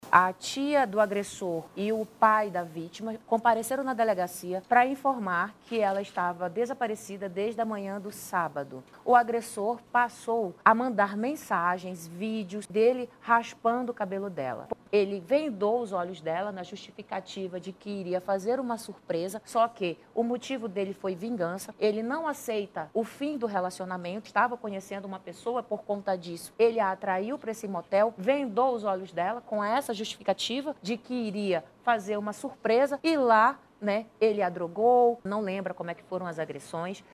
Em coletiva de imprensa nessa segunda-feira